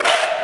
滑板 " 转弯
描述：滑板弹跳研磨
标签： 反弹 研磨 滑板
声道立体声